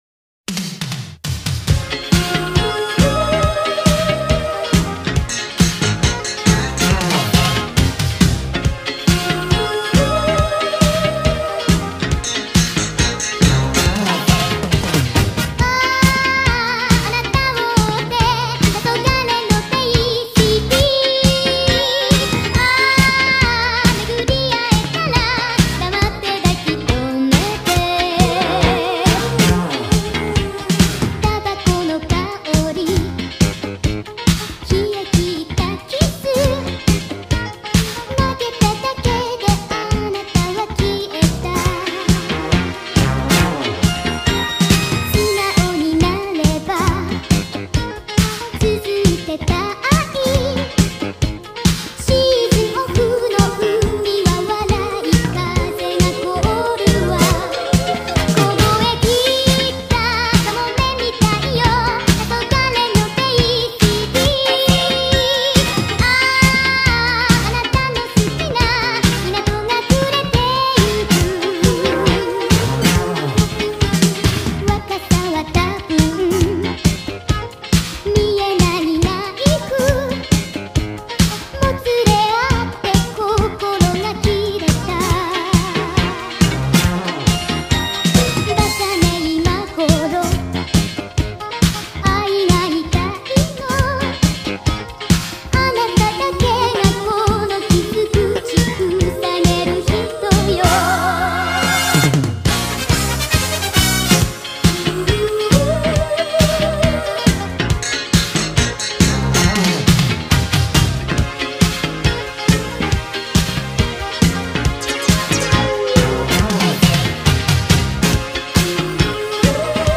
با صدای بچه (افزایش سرعت)